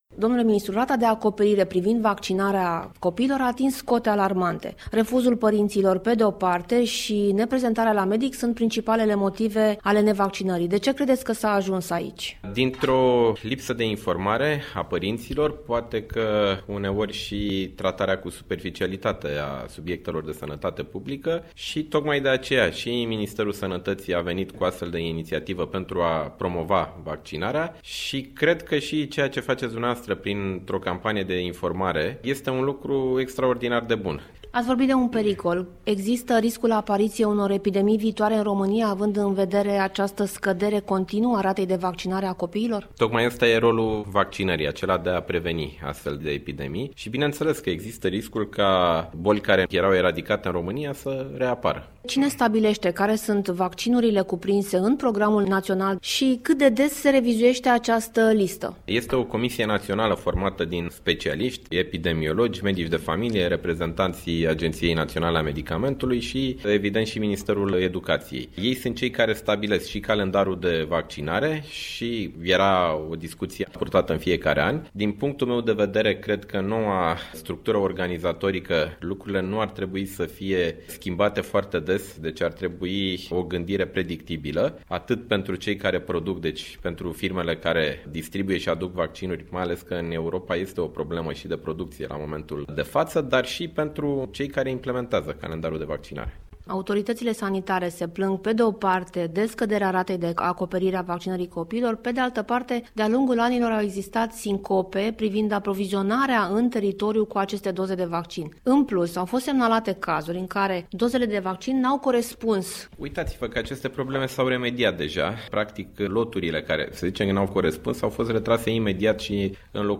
Ministrul Sănătăţii, Nicolae Bănicioiu, a vorbit la RRA despre viitoarea lege care va reglementa vaccinarea în ţara noastră: